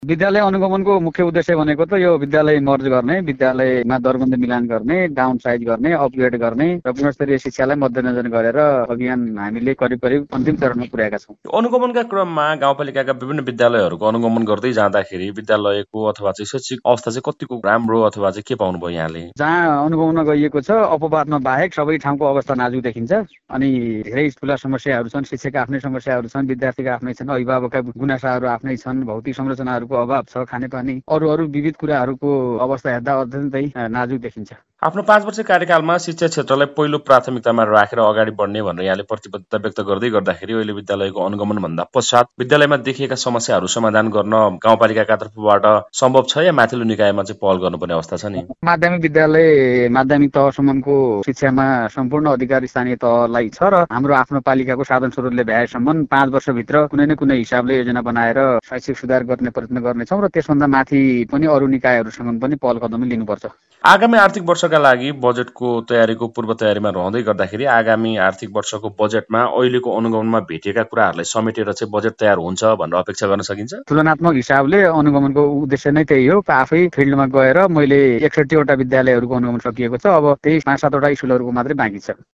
कुराकानी :-
KURAKANI-DAMBAR-BC.mp3